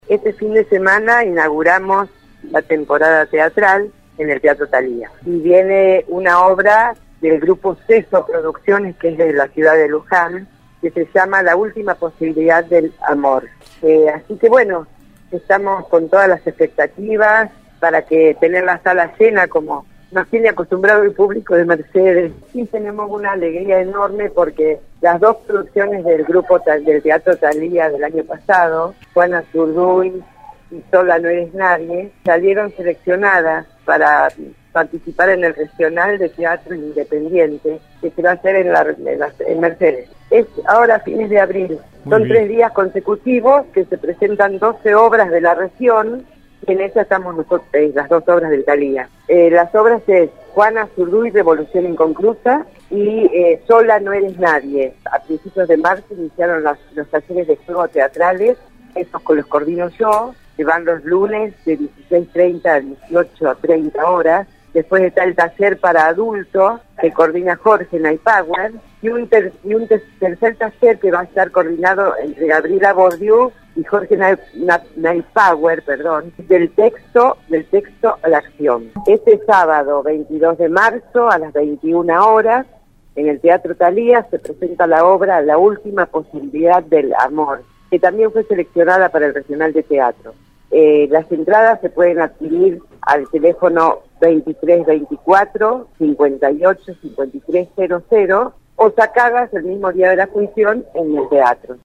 EN RADIO UNIVERSO 93.1